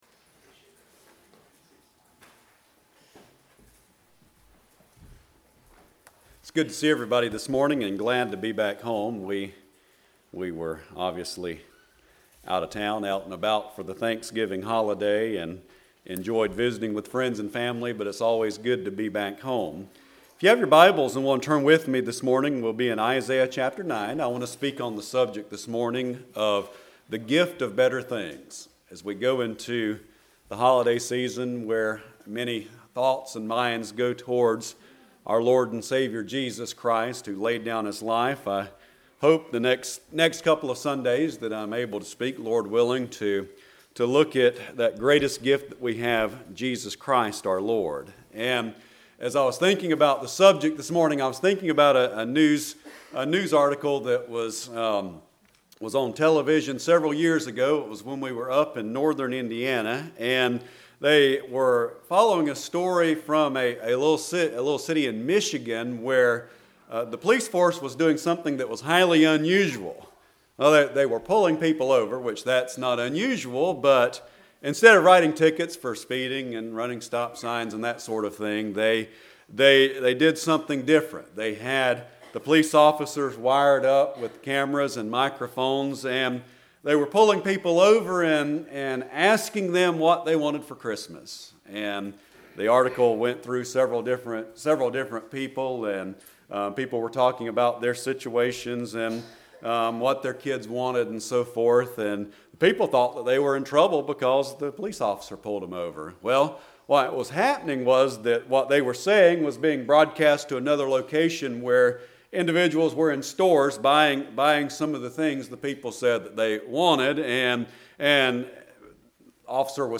12/01/19 Sunday Morning
Passage: Isaiah 9:1-7 Service Type: Sunday Morning